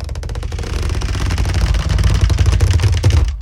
spiderweb.ogg